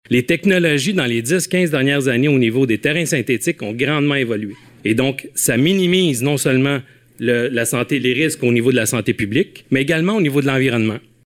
Quatre d’entre eux se sont adressés à leurs élus à ce sujet à la séance du conseil du 30 août.